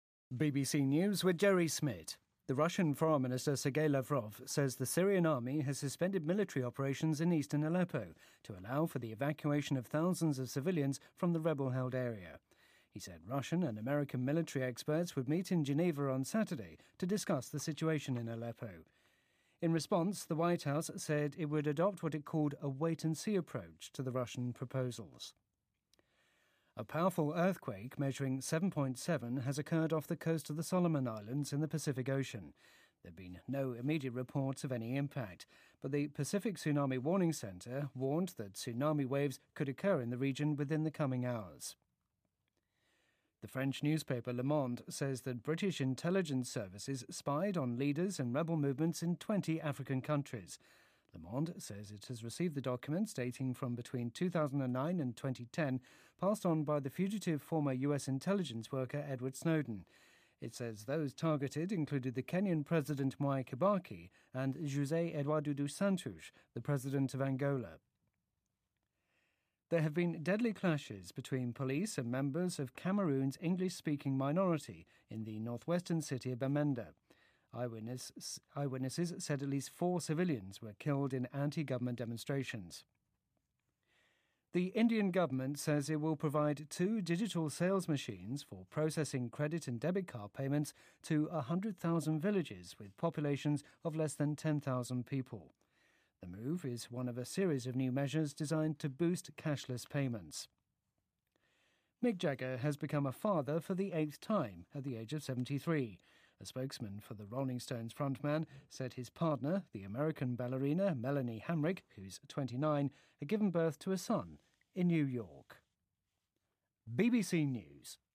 BBC news,滚石主唱贾格尔第8次再为人父